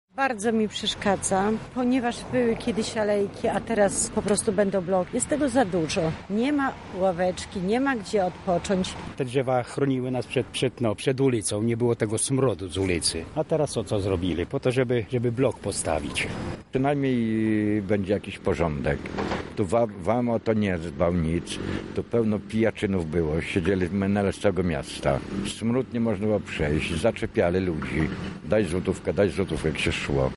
Co myślą mieszkańcy na ten temat spytał nasz reporter: